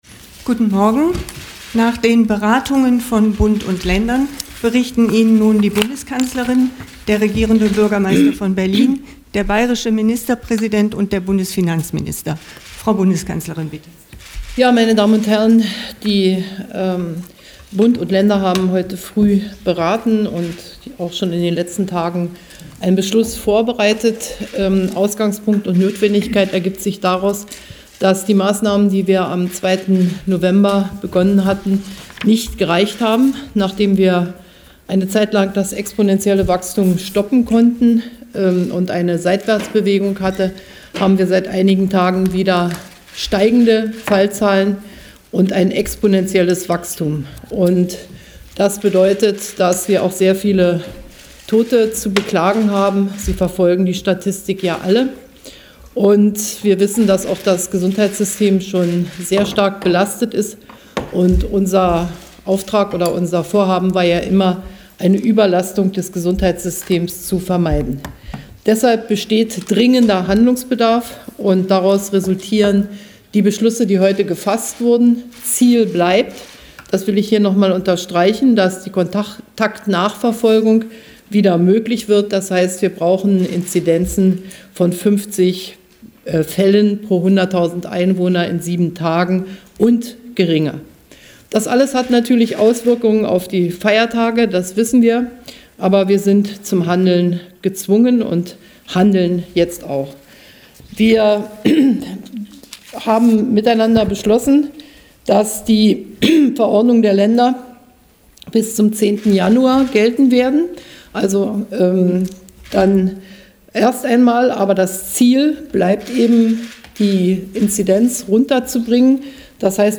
Pressekonferenz nach dem Bund-Länder-Beschluss zur Bekämpfung der Corona-Pandemie